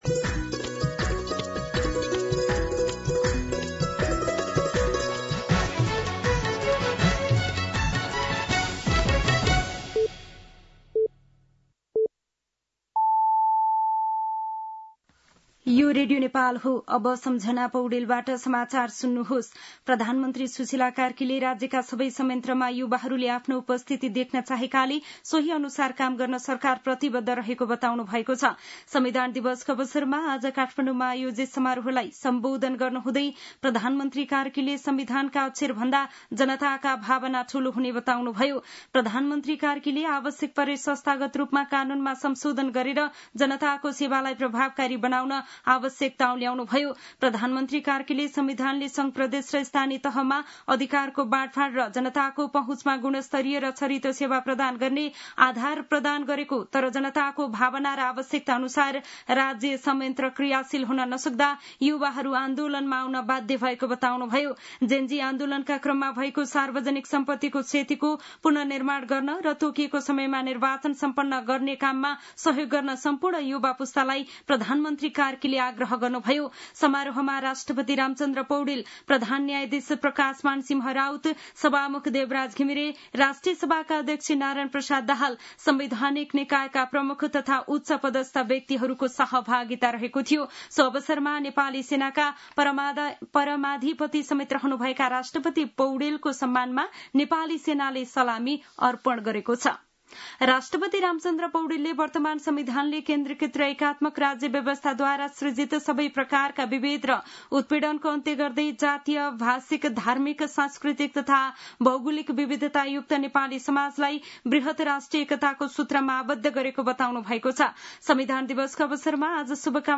दिउँसो १ बजेको नेपाली समाचार : ३ असोज , २०८२
1-pm-Nepali-News-1.mp3